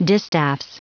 Prononciation du mot : distaffs
distaffs.wav